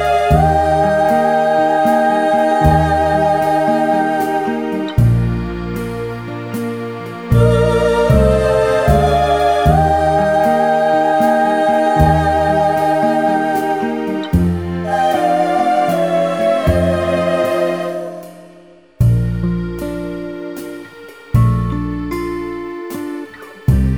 Crooners 1:53 Buy £1.50